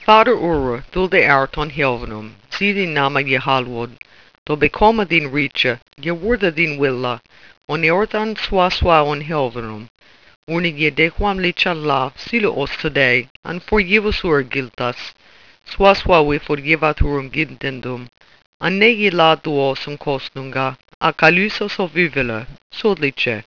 หรือลองมาฟังการอ่านข้อความข้างบนเป็นภาษาอังกฤษโบราณดูสิครับ แล้วจะรู้ว่ามันแทบจะไม่เหมือนภาษาอังกฤษปัจจุบันเลย คลิ๊กที่นี่ ->
OldEng.wav